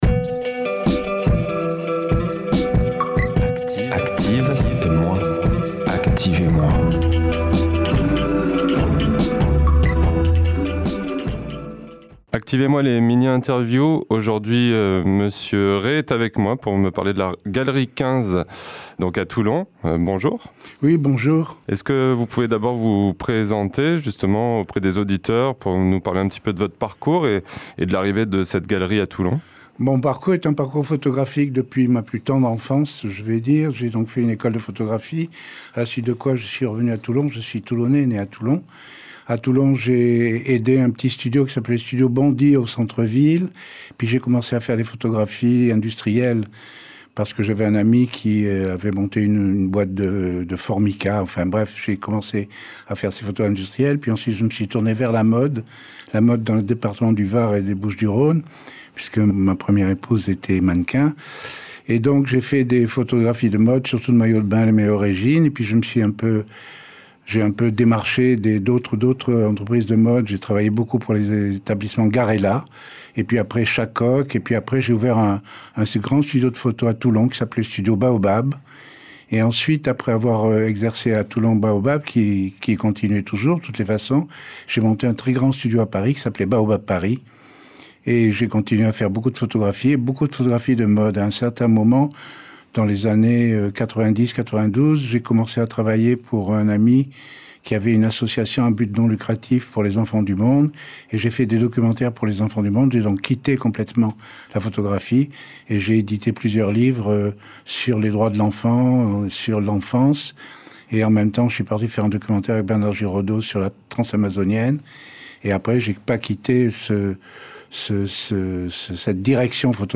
Entretien réalisé par